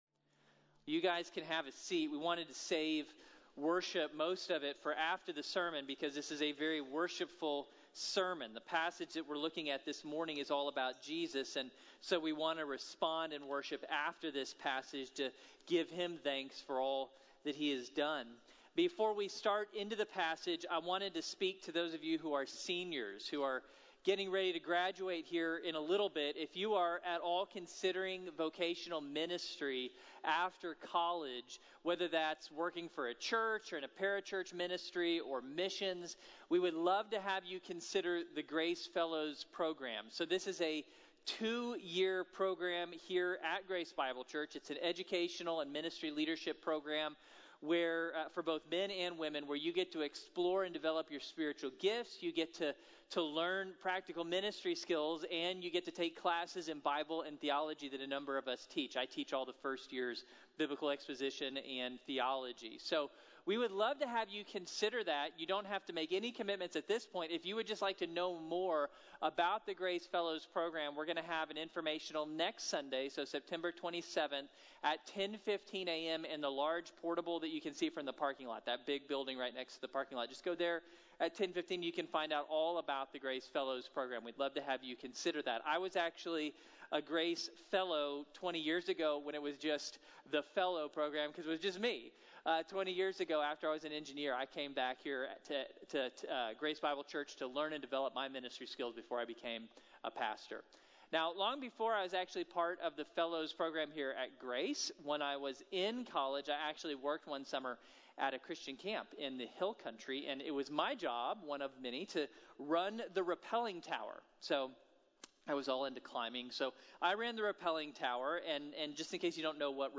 Saved by Faith | Sermon | Grace Bible Church